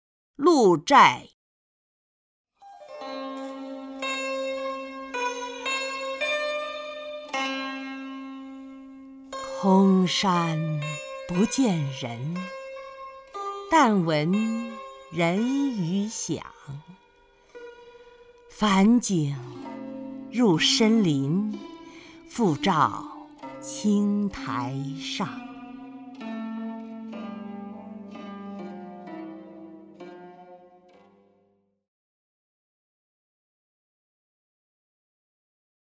曹雷朗诵：《鹿柴》(（唐）王维) （唐）王维 名家朗诵欣赏曹雷 语文PLUS